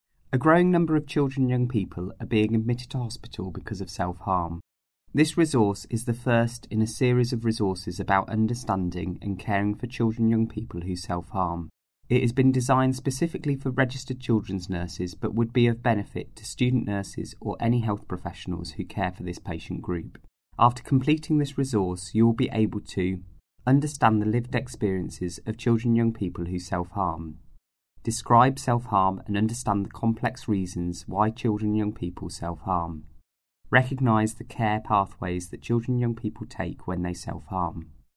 Narration audio (MP3) Narration audio (OGG) Contents Home What is this learning resource for?